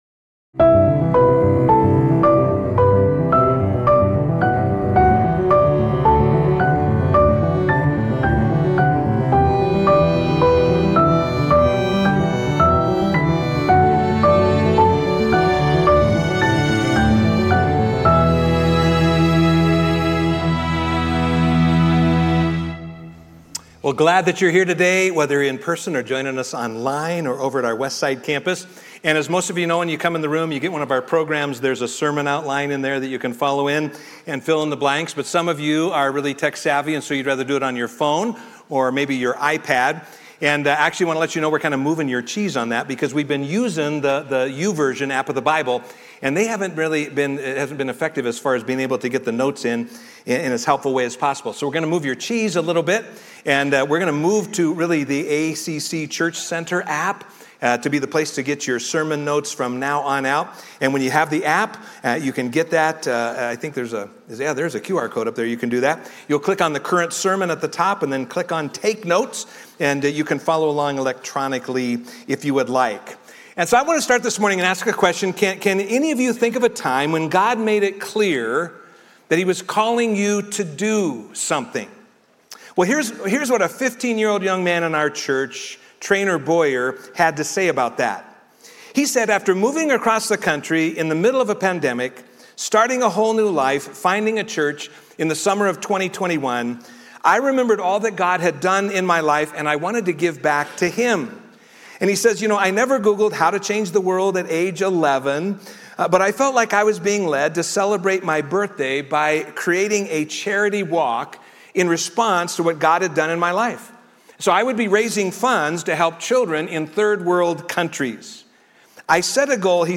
A message from the series "Detours to Destiny."